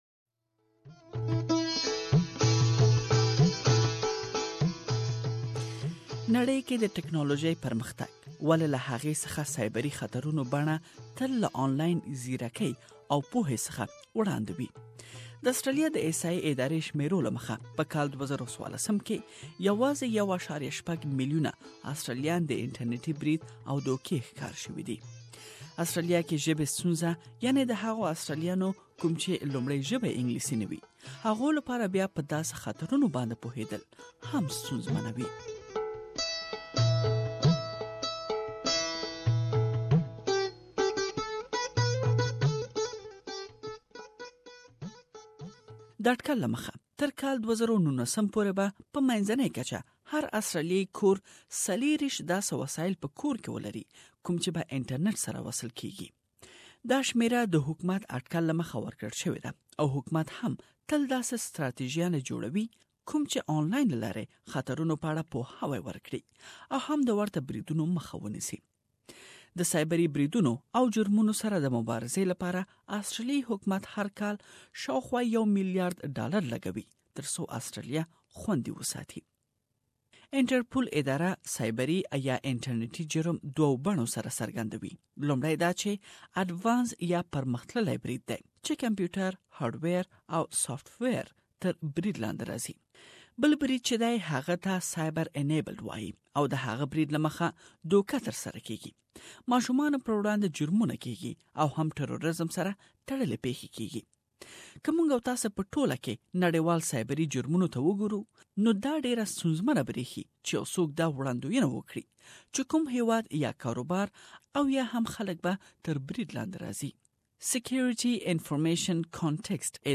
نن مو يادې موضوع ته کتنه کړې او دا چې د cyber space خطر څه دی او څنکه مونږ انلاين له لارې خپل محرميت خوندي ساتلی ش پدې اړه تيار شوي رپوټ ته غوږ شئ.